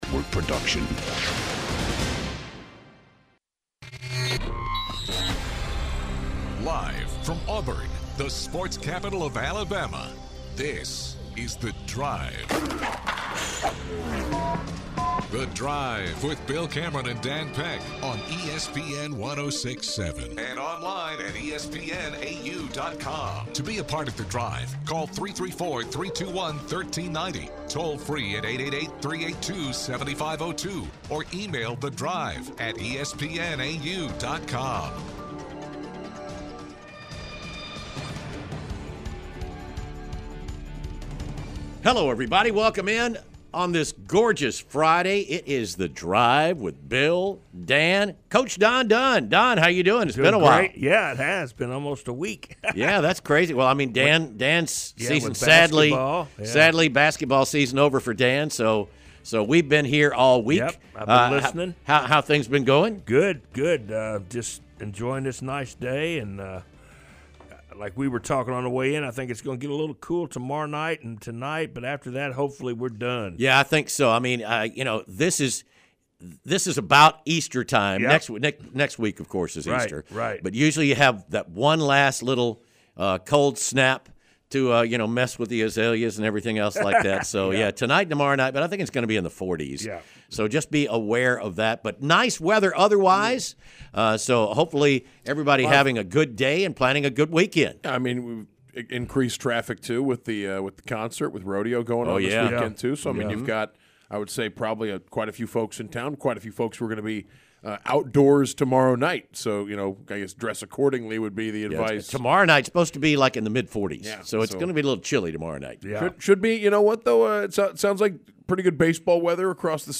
and take calls from listeners about the future of the Auburn Men's Basketball program.&nbsp